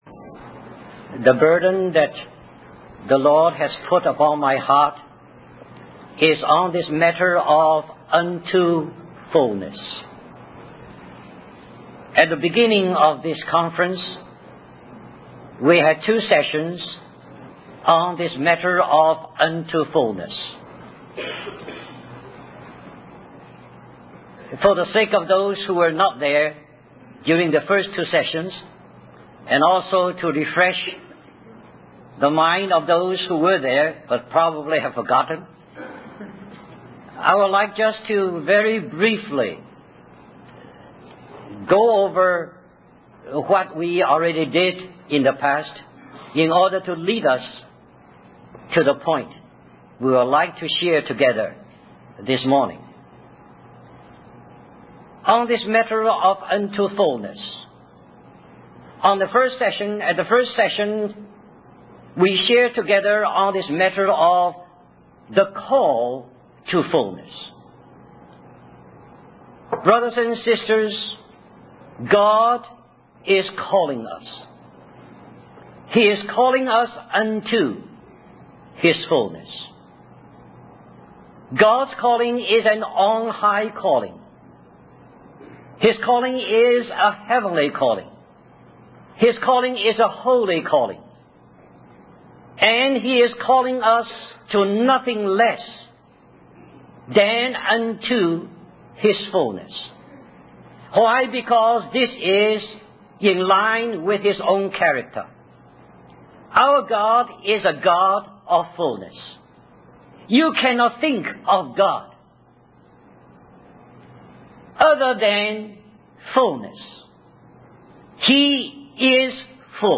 In this sermon, the speaker discusses the concept of love and how it drives believers towards God.